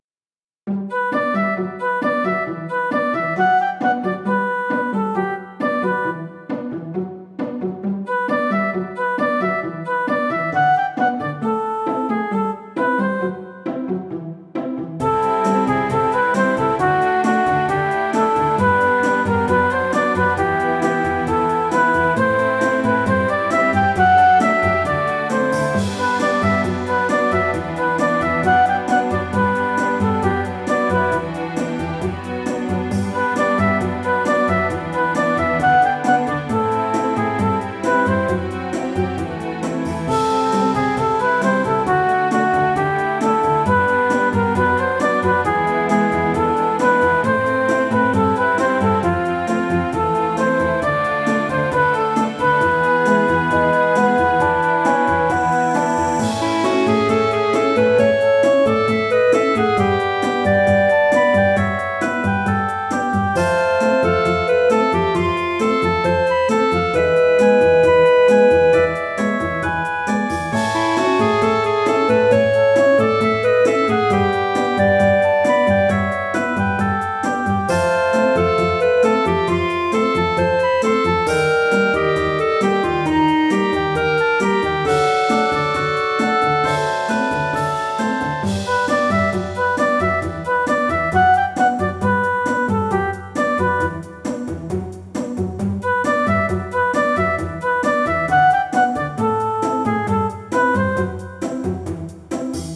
-oggをループ化-   日常 軽やか 2:04 mp3